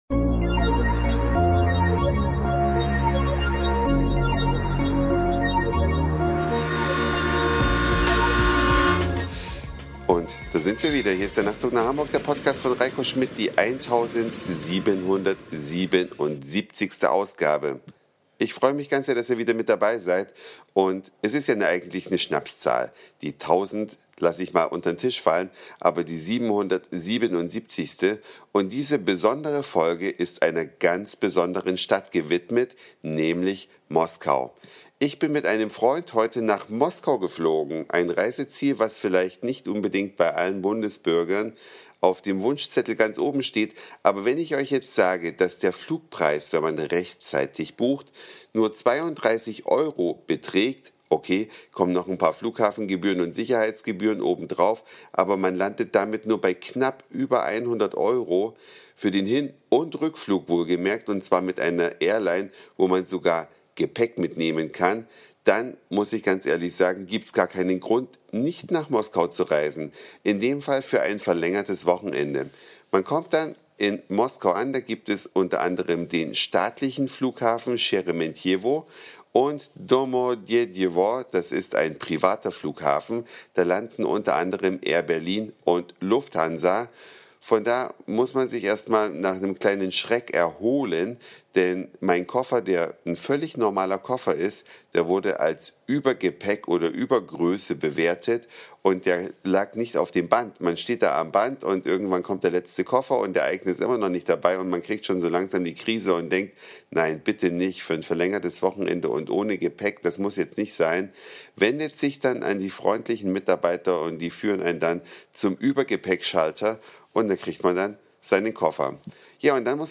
Authentische Fahrt ins Stadtzentrum Bootstour auf der Moskwa